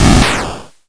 ihob/Assets/Extensions/explosionsoundslite/sounds/bakuhatu120.wav at master
bakuhatu120.wav